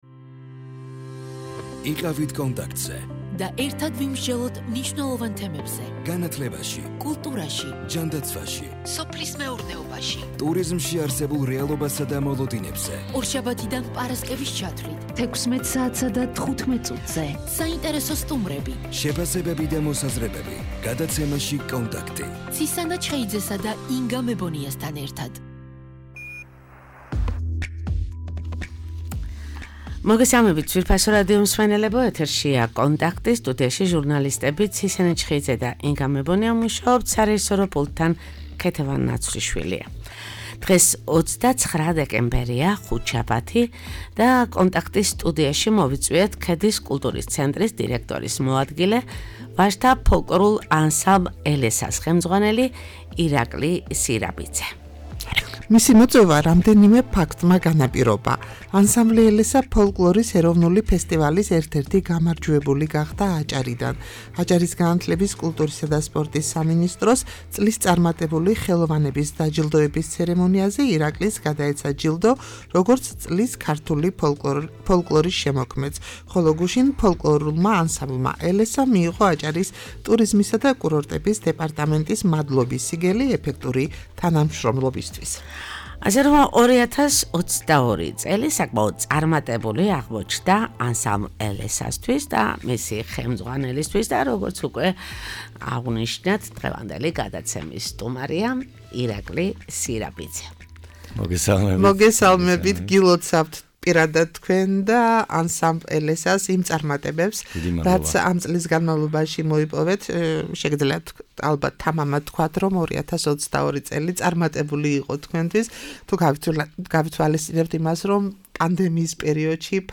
ფოლკლორული ანსამბლი "ელესა"